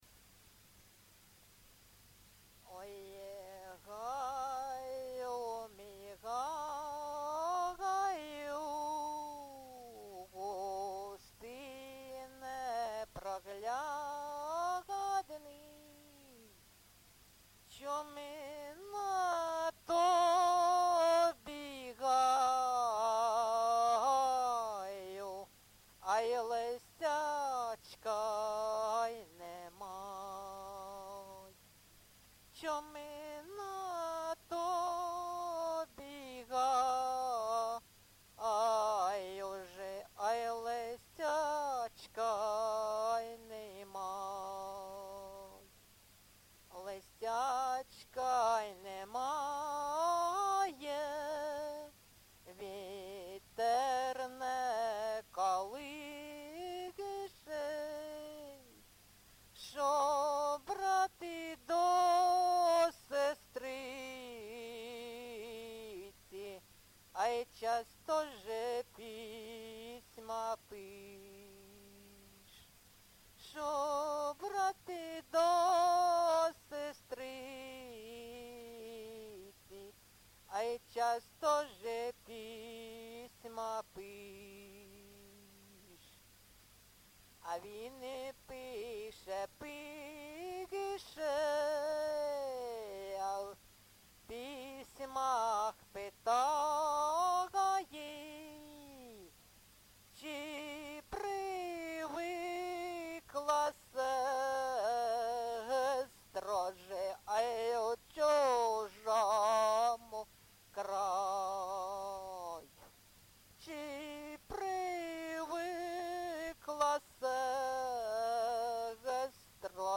ЖанрПісні з особистого та родинного життя
Місце записум. Ровеньки, Ровеньківський район, Луганська обл., Україна, Слобожанщина